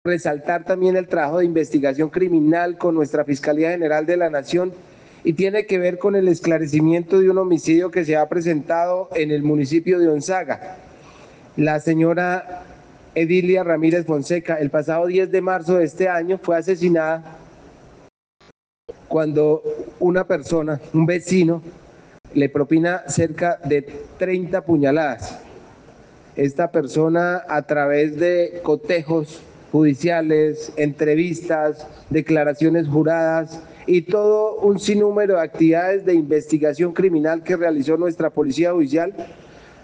Coronel Néstor Rodrigo Arévalo Montenegro, comandante de la policía de Santander